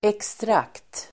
Ladda ner uttalet
Uttal: [ekstr'ak:t]